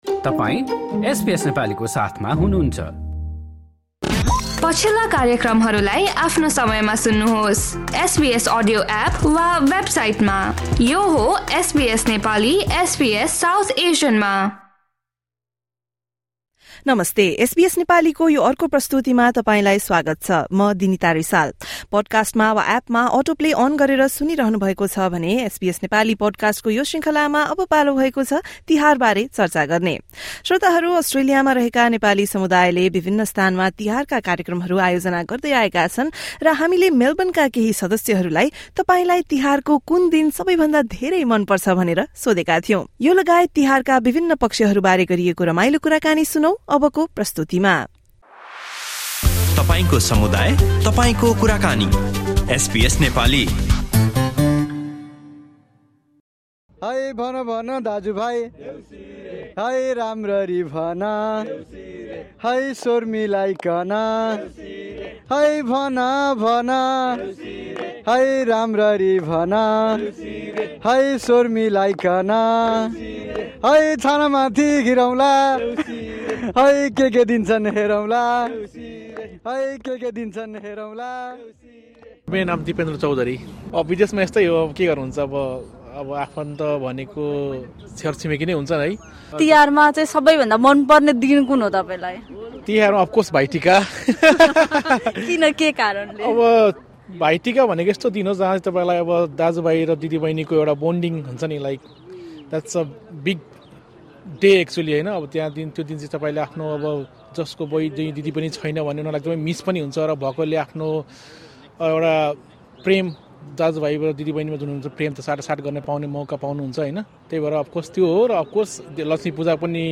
तपाईँलाई तिहारको कुन दिन सबैभन्दा धेरै मन पर्छ? मेलबर्नको नेपालीका समुदायका सदस्यहरूले यो प्रश्नका साथसाथै तिहारका आफ्ना केही अविस्मरणीय यादहरू बारे एसबीएस नेपालीसँग गरेको कुराकानी सुन्नुहोस्।